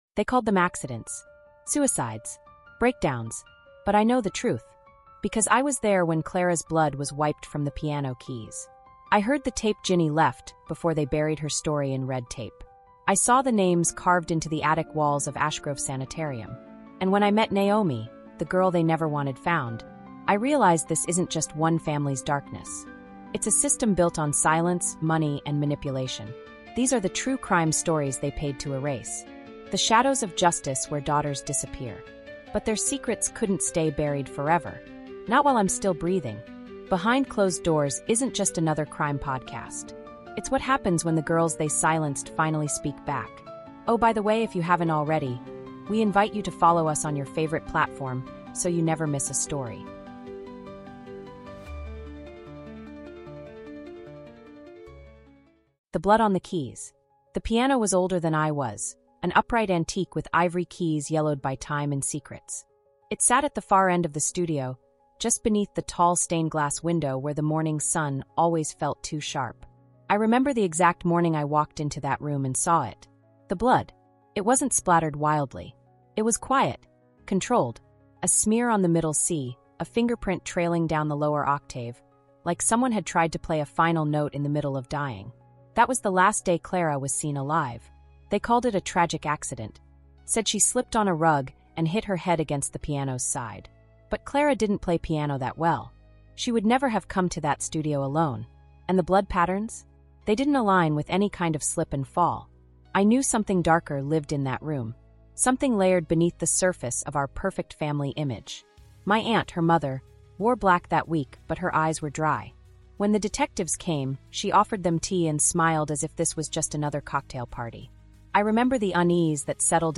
What if the perfect family photo was hiding a crime scene? Behind Closed Doors is a spine-chilling, first-person true crime audiobook that peels back the polished surface of elite family life to reveal a world of murder tales, conspiracy, and corruption. Told through the haunting voice of a survivor, this emotionally immersive narrative unravels a decades-long cover-up stretching across church graveyards, abandoned sanitariums, and Carver’s Estate—where daughters disappeared in silence, and the music masked the screams.